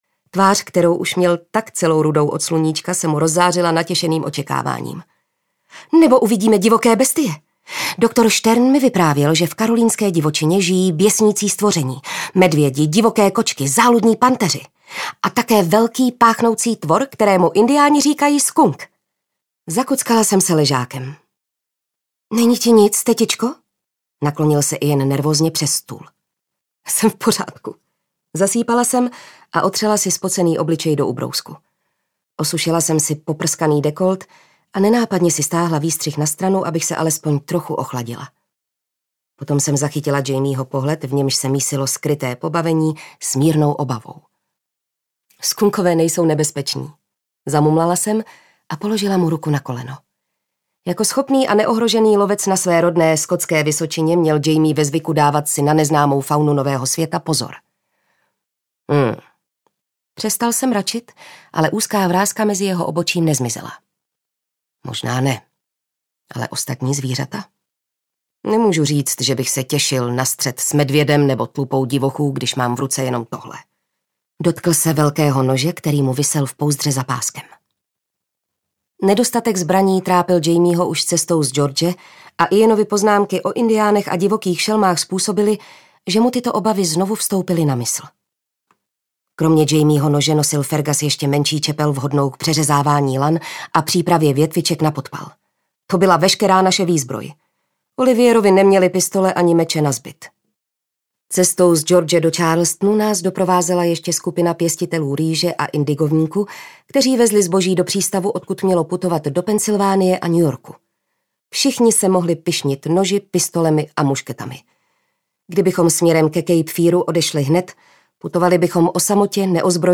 Bubny podzimu audiokniha
Ukázka z knihy